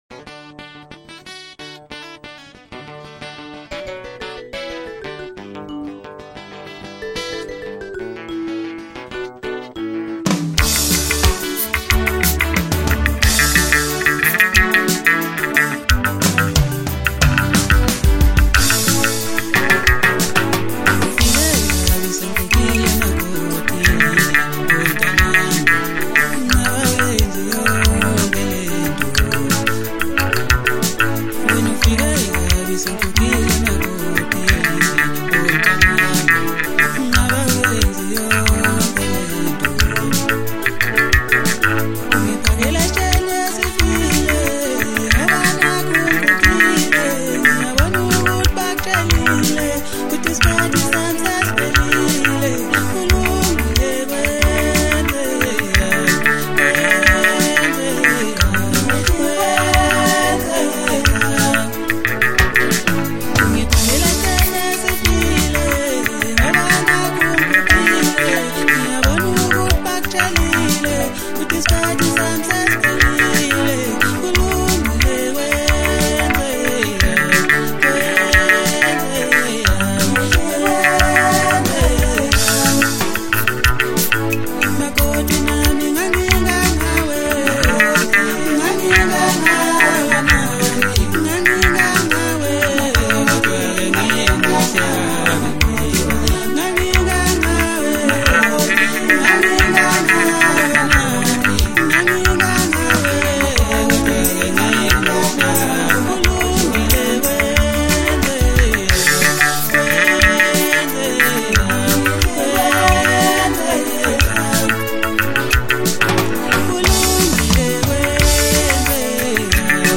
MASKANDI MUSIC
maskandi song